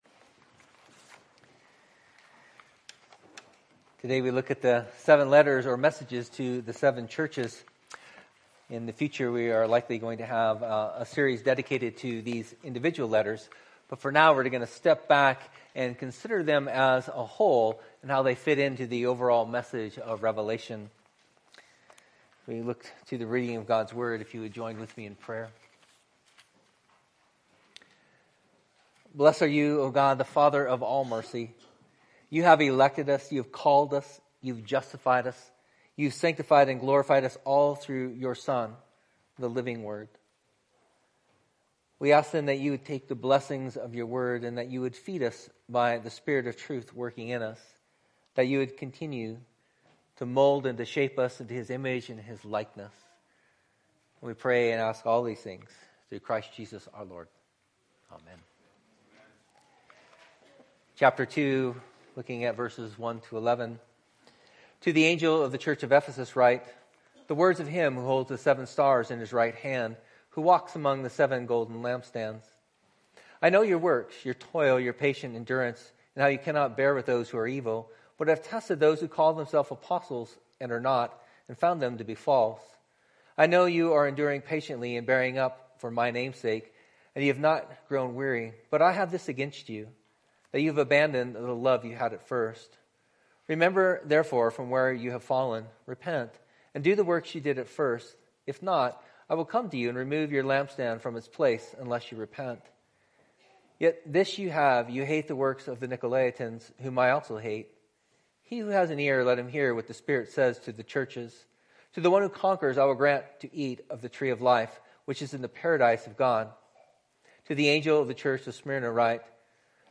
Type Morning